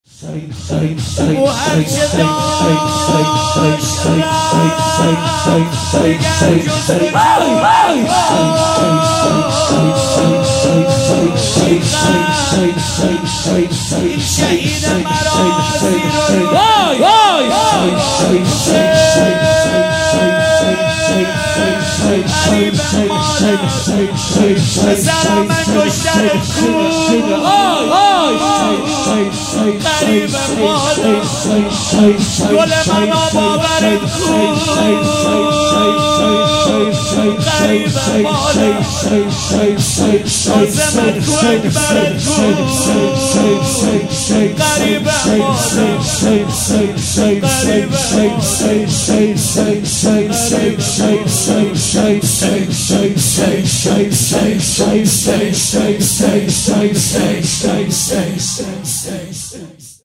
ایام فاطمیه 1441